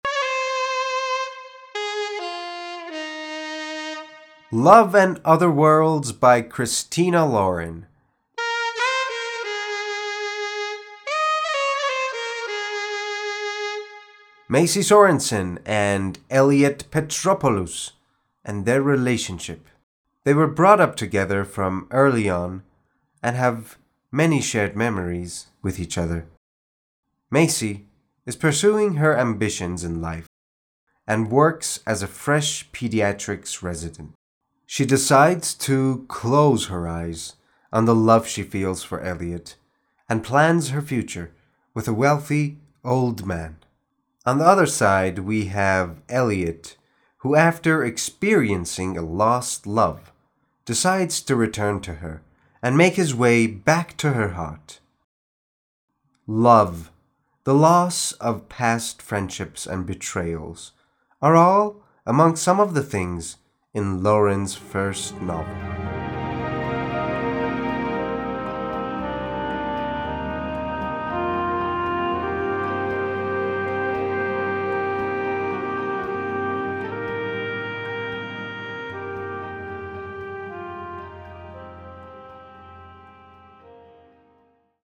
معرفی صوتی کتاب Love and Other Words